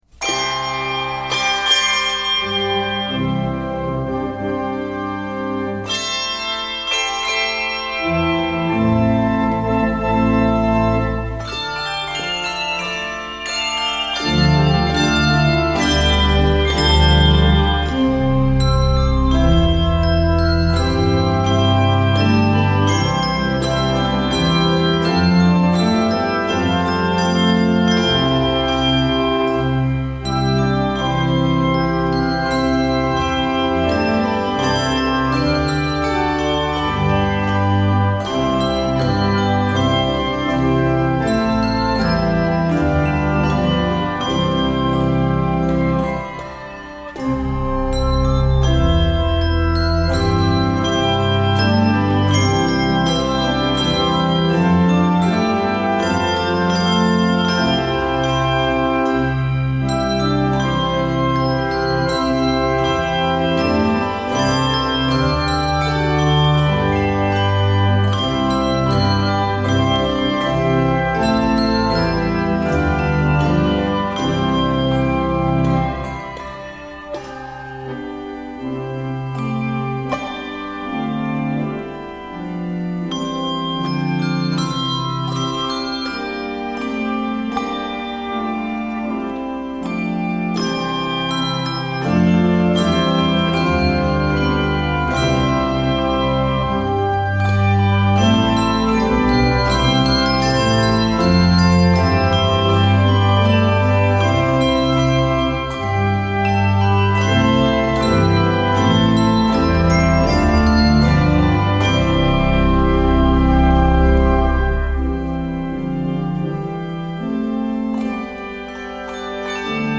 dignified and stately arrangement
Keys of C Major and G Major.